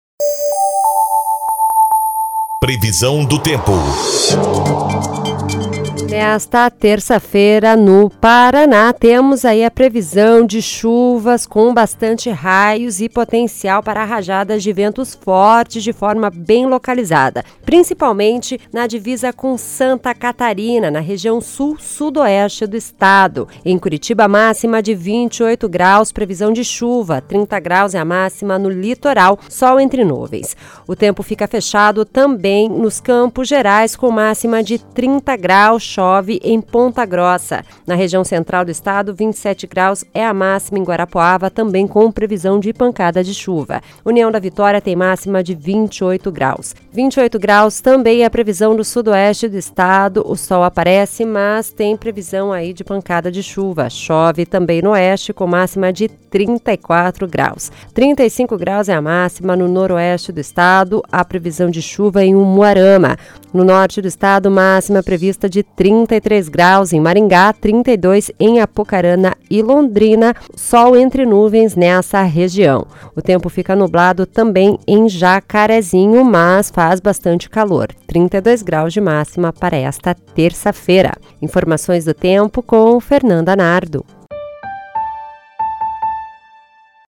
Previsão do Tempo (27/02)